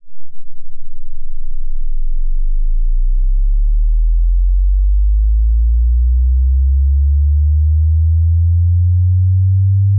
Voici 4 fichiers au format ".wav" de sons de fréquences variables.
Son sinusoïdal de fréquences variants de 1 à 100 [Hz] en 10 secondes.
Au début, on entend rien.